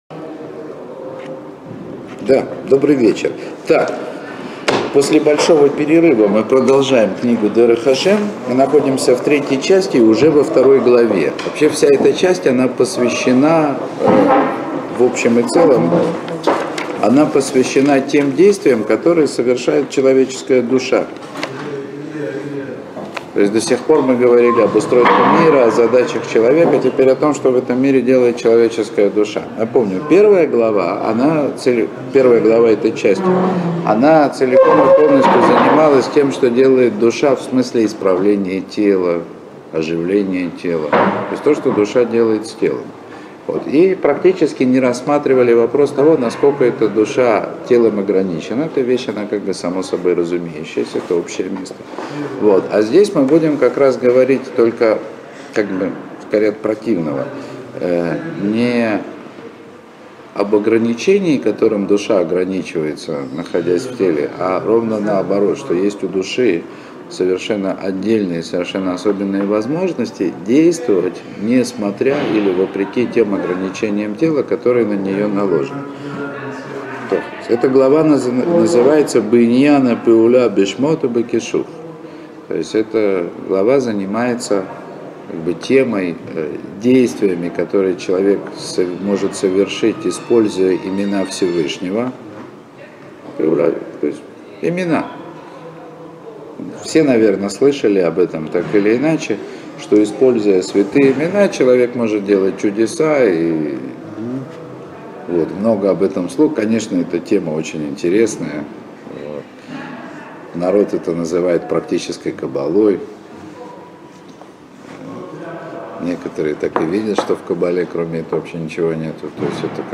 Уроки по книге Рамхаля Дерех Ашем.